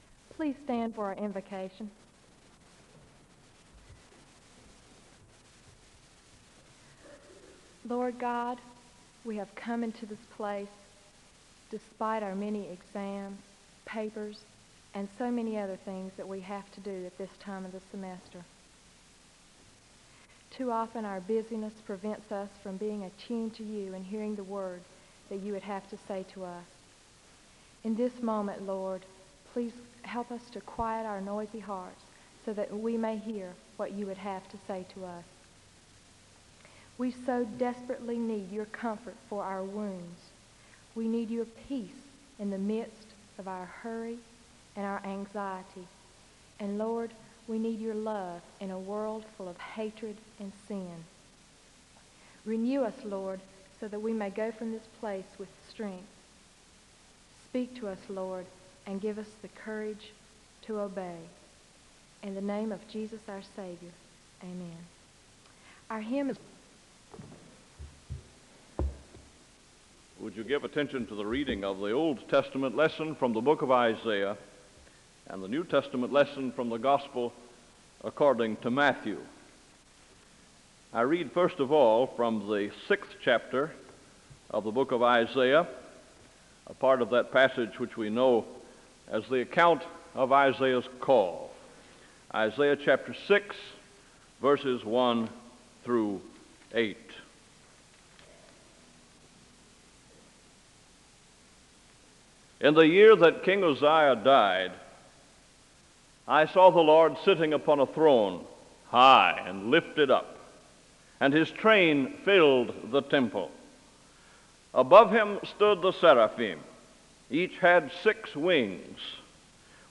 The service begins with a word of prayer (00:00-01:07). The speaker delivers the Scripture reading from Isaiah 6:1-8 and Matthew 9:35-38 (01:08-04:30).
SEBTS Chapel and Special Event Recordings SEBTS Chapel and Special Event Recordings